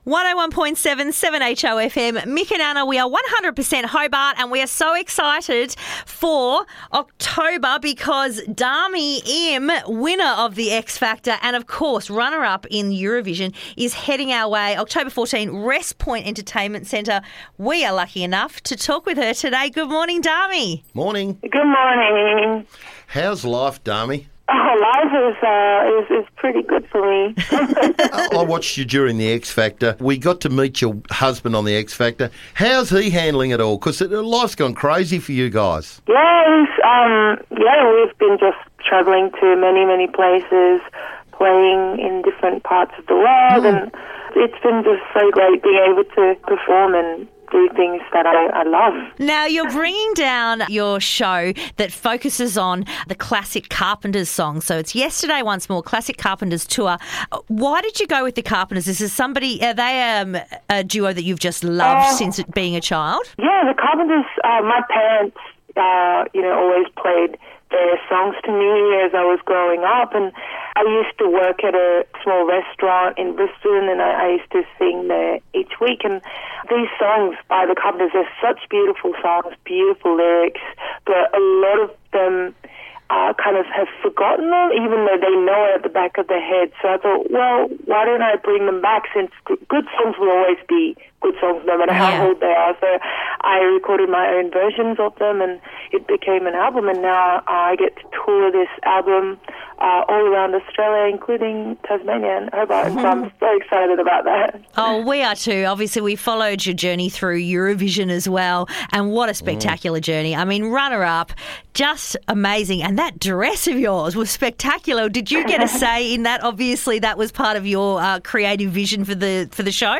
had a chat with Dami Im ahead of her visit to Hobart